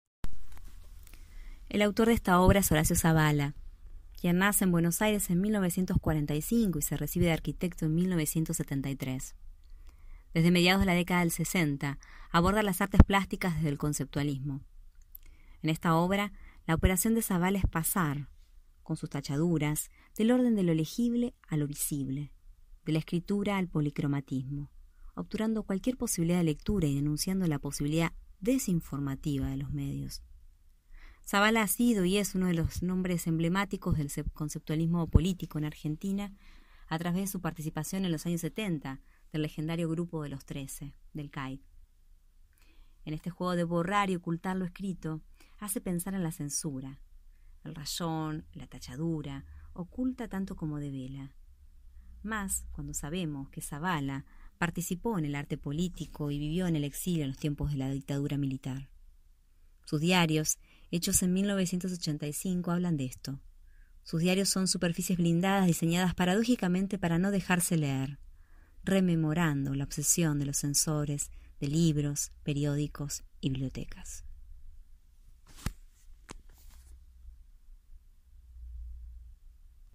Audioguía adultos